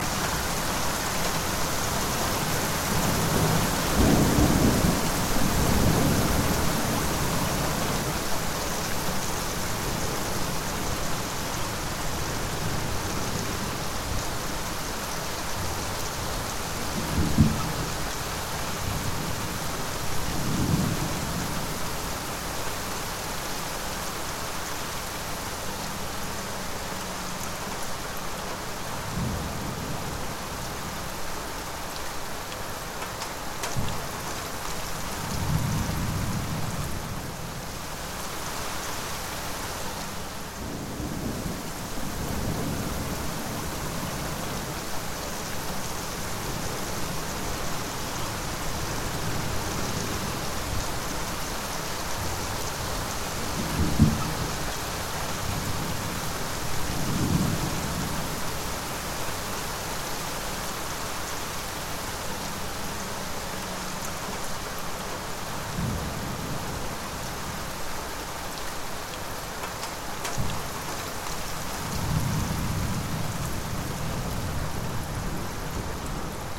Звуки плохой погоды
Шум ливневого дождя, вьюга за окном